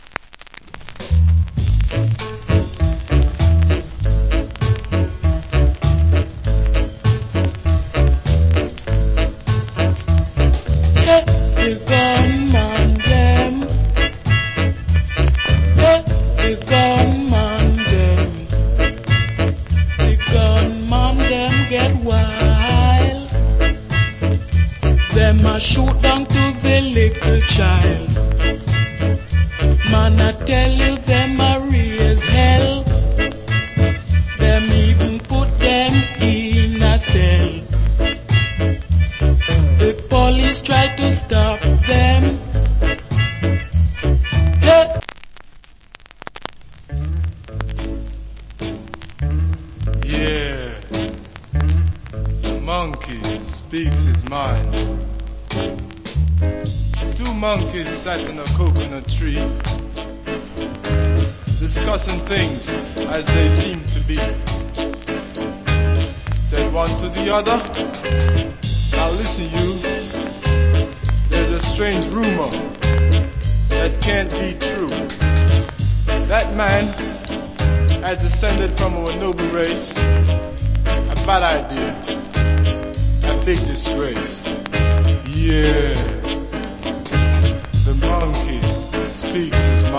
Rude Boy Rock Steady Vocal.